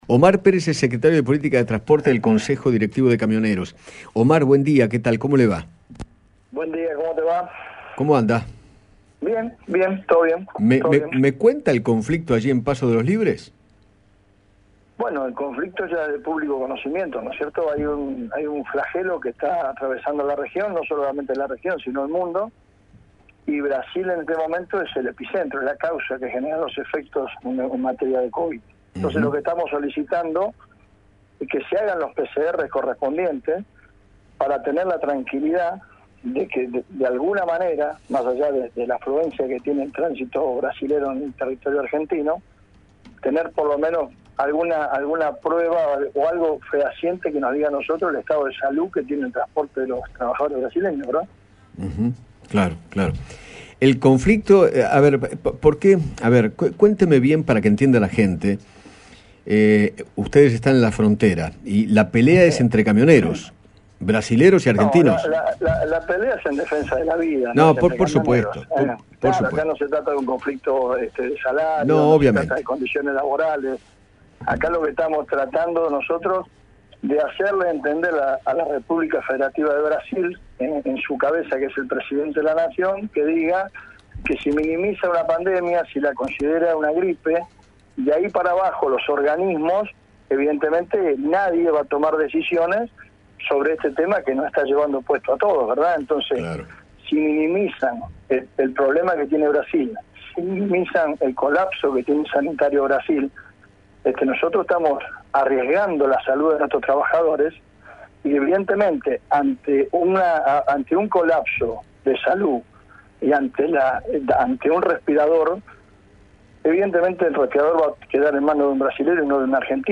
dialogó con Eduardo Feinmann acerca de la medida de fuerza que tomaron y que genera tensión en la frontera con Brasil.